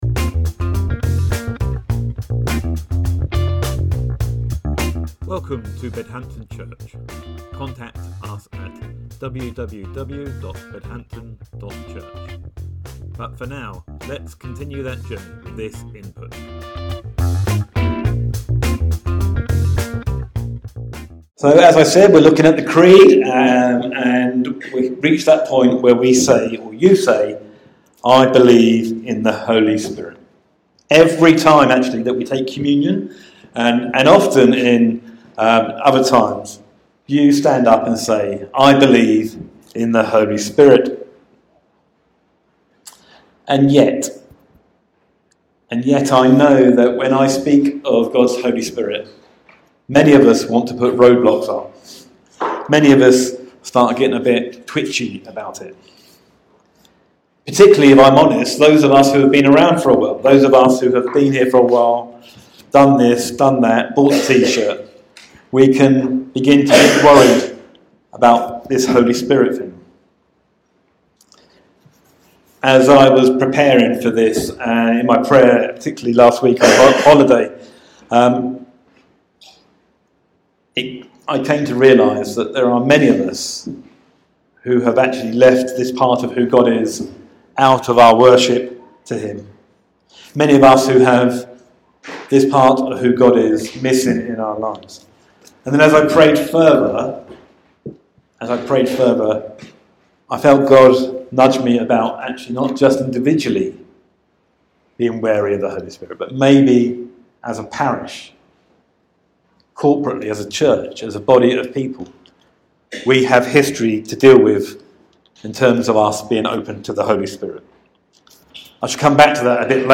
Sermon September 15th, 2024 - Creeds: I believe in the Holy Spirit… - Bedhampton Church